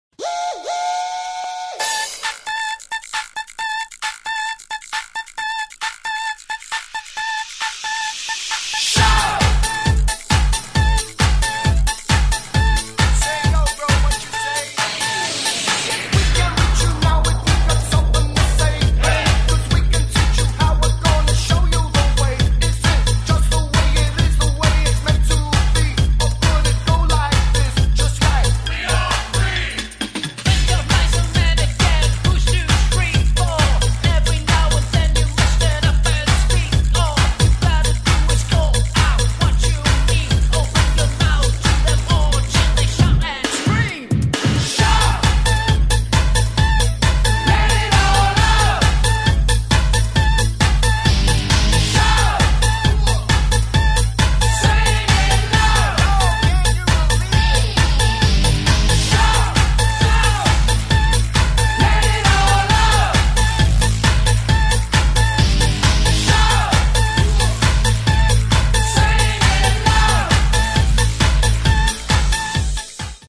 • Качество: 128, Stereo
мужской вокал
громкие
dance
house
Hard House
hip-house
гудок паровоза